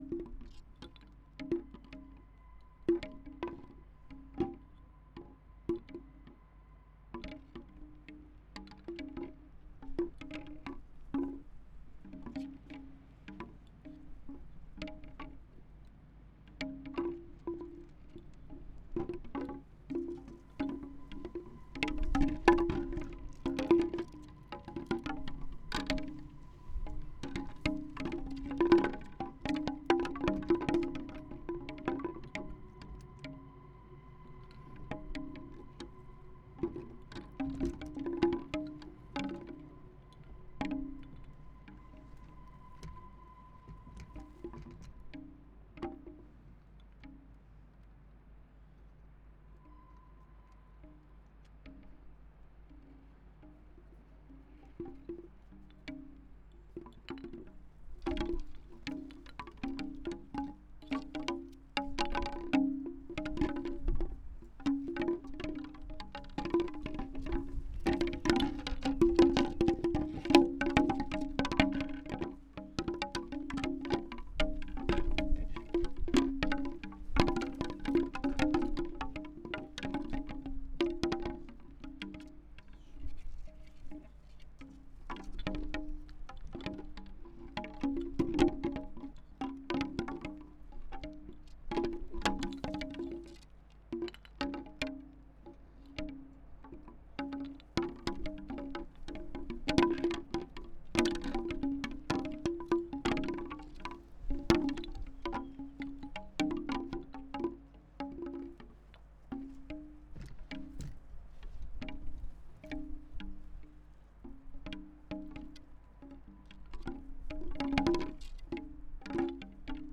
Woodwinds: Windspiel aus Bambus , 6 Röhren in unterschiedlichen Längen Set Up: 4 AKG CP 411 PP an je 1 Bambusröhre, in Mackie 1202VLZ4 (Kanäle 1 - 4), Insert Out in Zoom H6. Rode NT4 seitlich zum Windspiel
Aufnahme (OKMII).flac (34:23) 6 Kanal sind 4 Akg mit Rode NT4.
2. Aufnahme (6 Kanal stereo).mp3